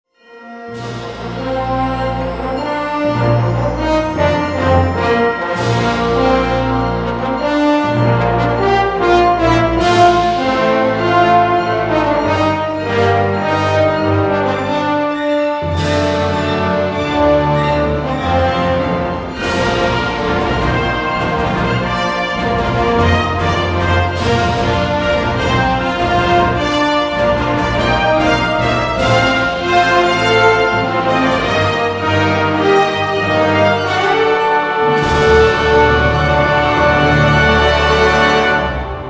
красивые
мотивирующие
без слов
инструментальные
бодрые
оркестр